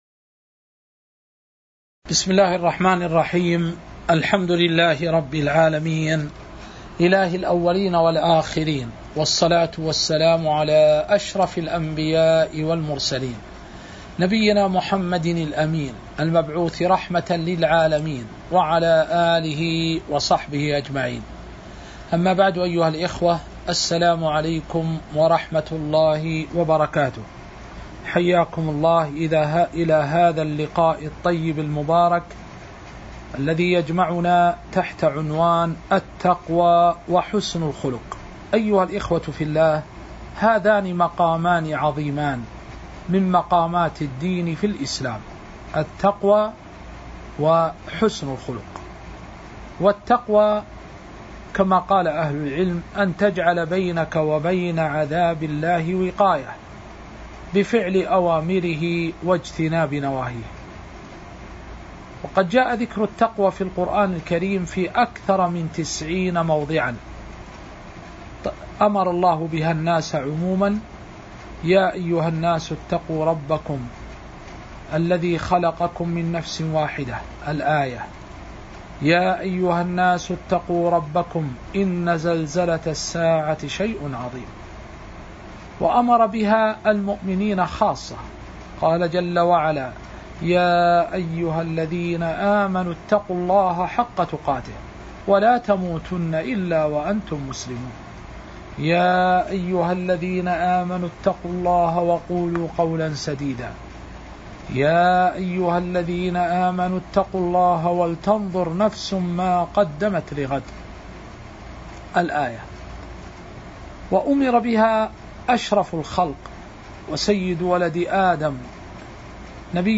تاريخ النشر ٢٧ ذو الحجة ١٤٤٣ هـ المكان: المسجد النبوي الشيخ